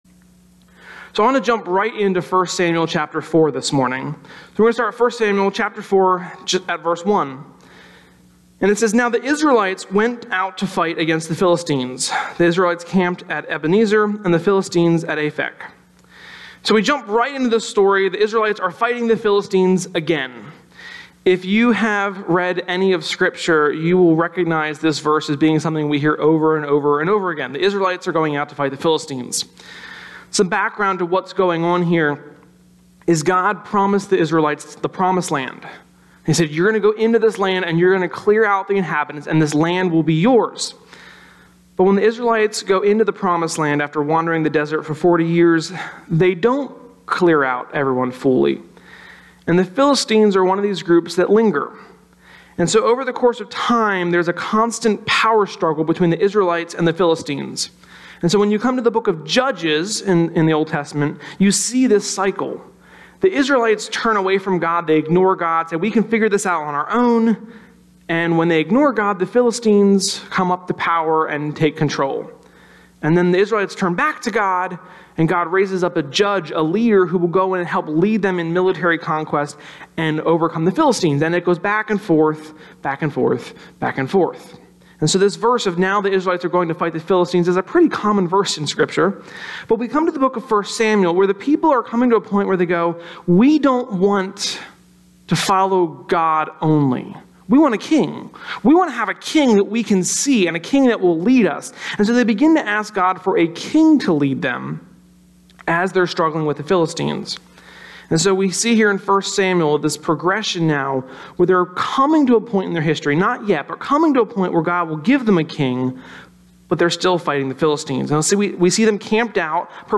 Sermon-10.1.17.mp3